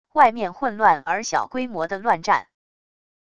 外面混乱而小规模的乱战wav音频